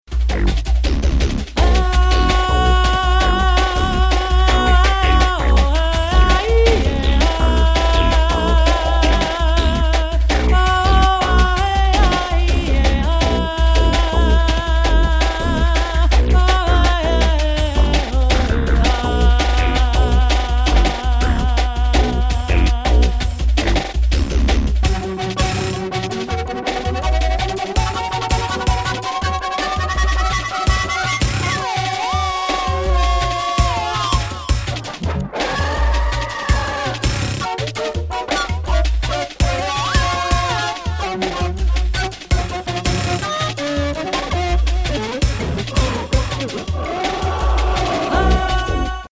flûtes